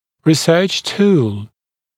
[rɪ’sɜːʧ tuːl][ри’сё:ч ту:л]исследовательский инструмент, способ исследования